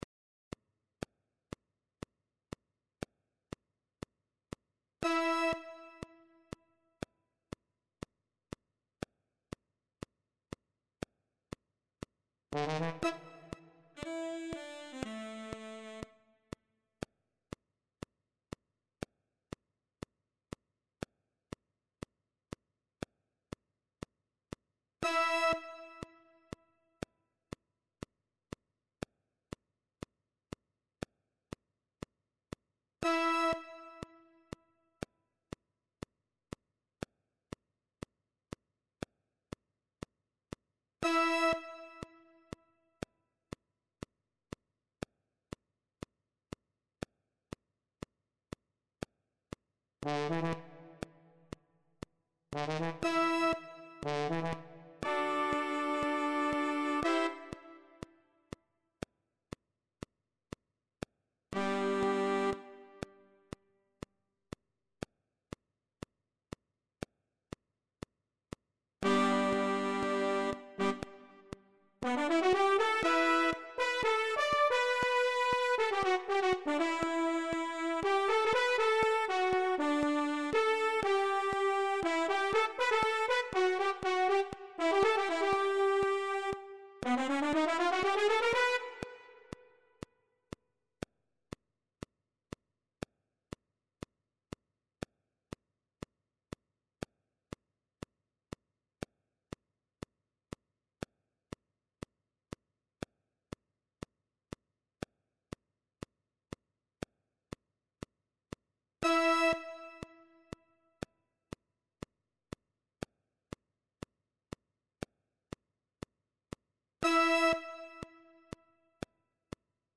TOM ORIGINAL.
Partitura do Naipe de Metais para os seguintes instrumentos:
1. Sax Tenor;
2. Sax Alto;
3. Sax Barítono;
4. Trompete; e,
5. Trombone.